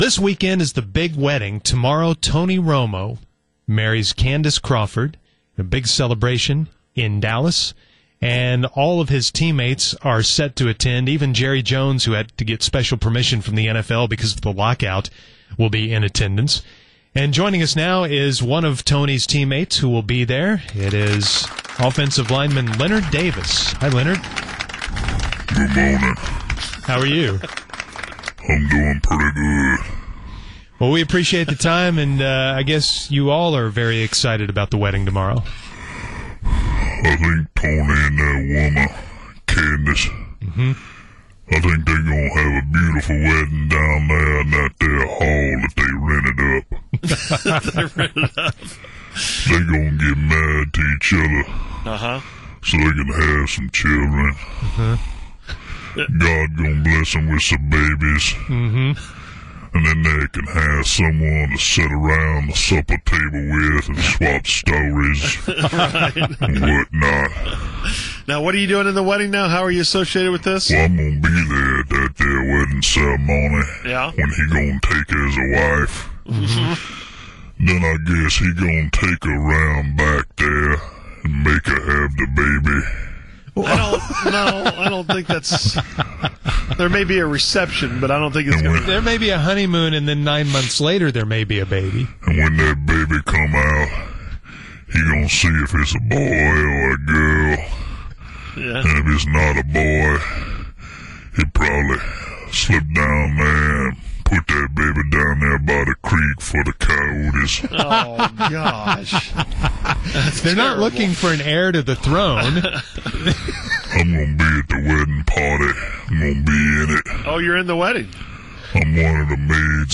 fake-leonard-davis-romo-wedding.mp3